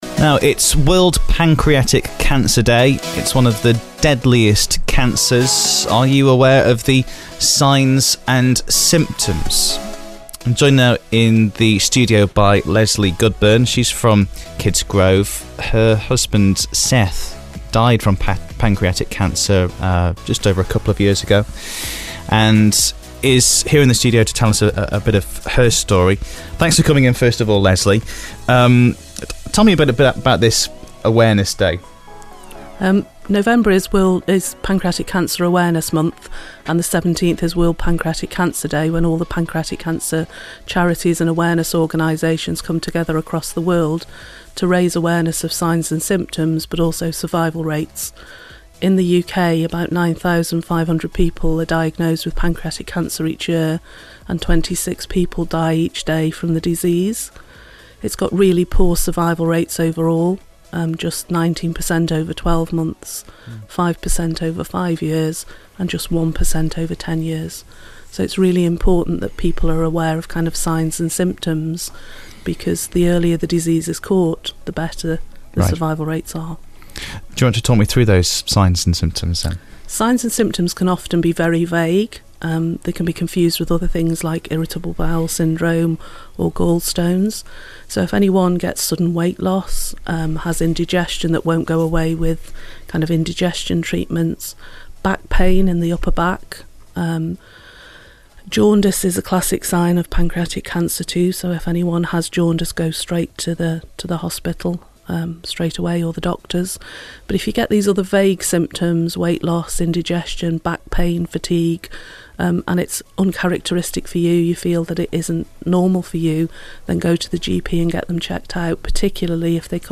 First broadcast on Cross Rhythms City Radio on 17 November 2016.